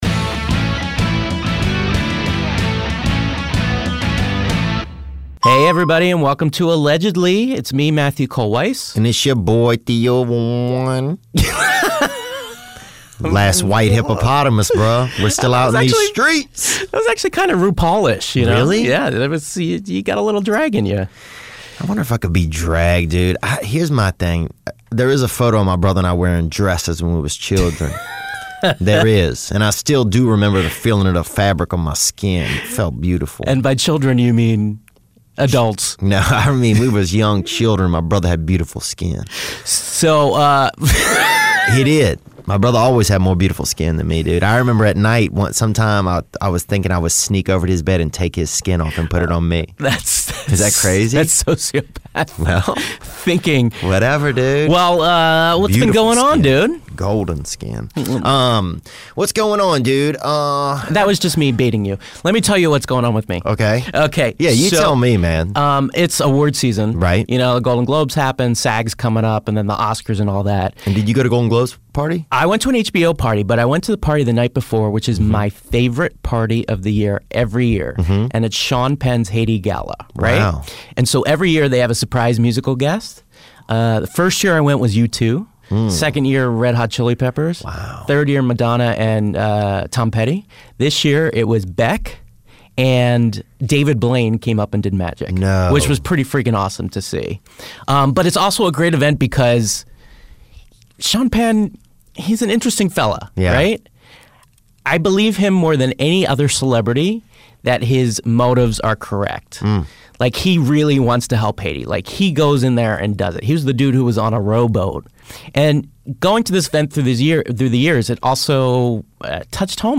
It's about to get all bro up in here when podcast genius, "The Man Show" co-host and "The 24 Hour War" director Adam Carolla fills the guest's chair on "Allegedly". The former "Celebrity Apprentice" star weighs in on the Donald (and his Twitter feud with the Ah-nold), the Kimmel, and the Pinsky, while discussing his new documentary about the long standing Ford versus Ferrari feud.